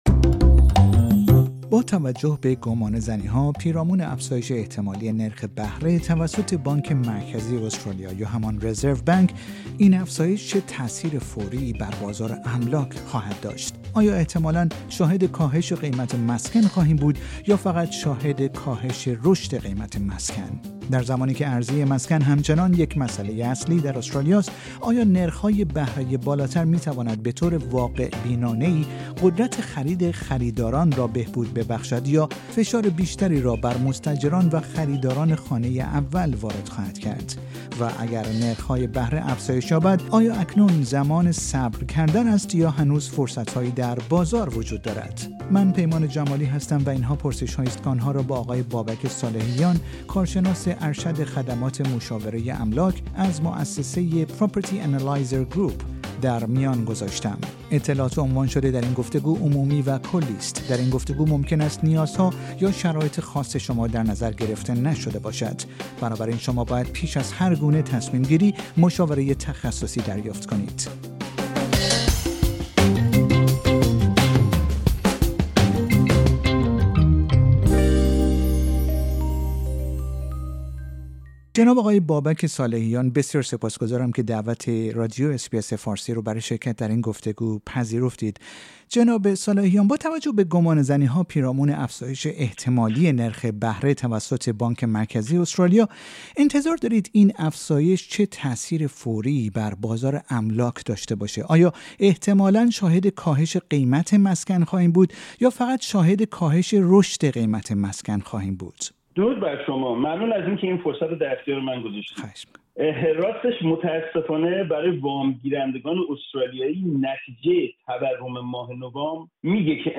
در گفتگویی با اس‌ بی‌ اس فارسی